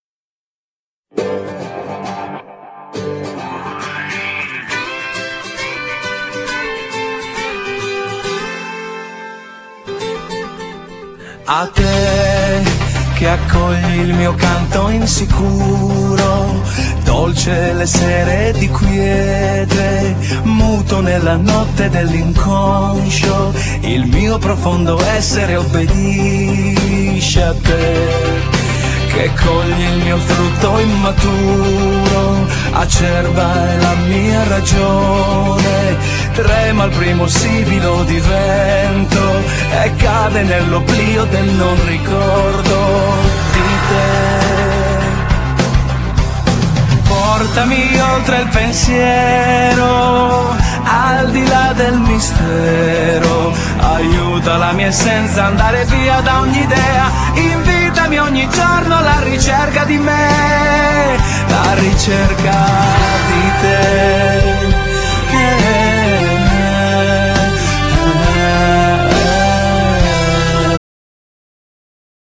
Genere : Pop